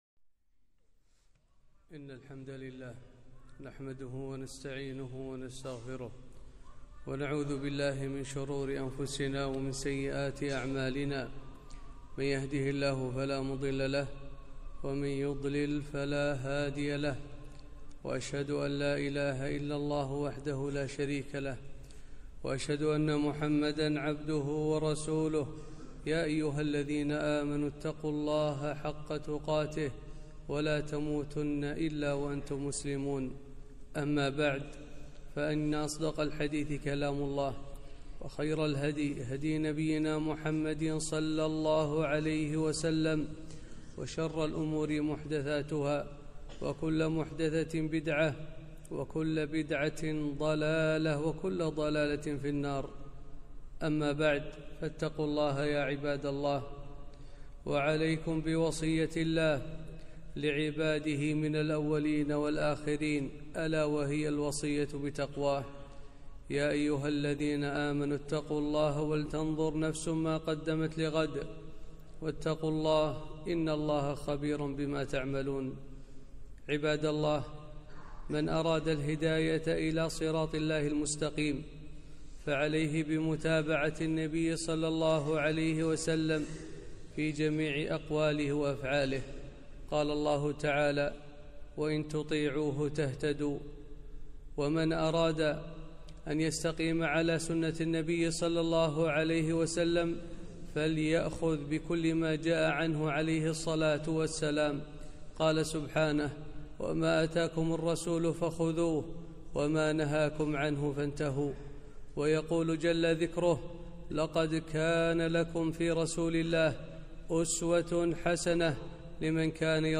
خطبة - فضل صلاة الاستسقاء